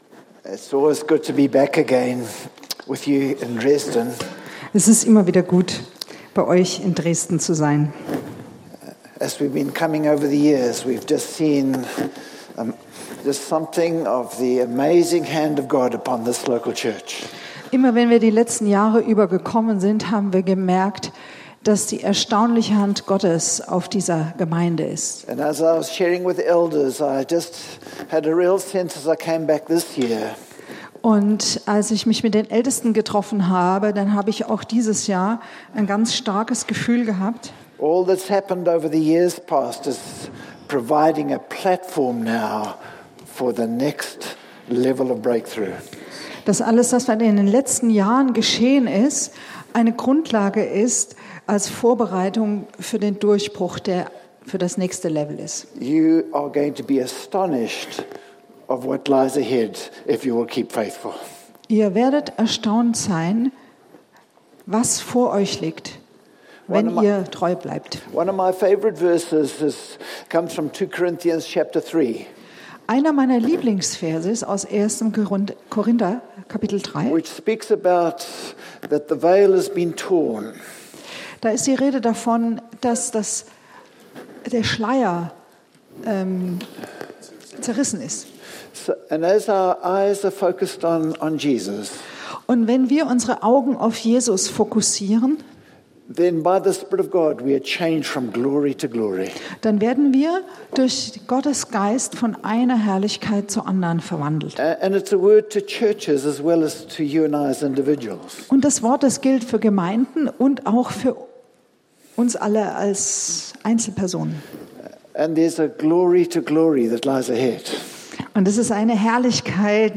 Eine predigt aus der serie "Gastpredigten."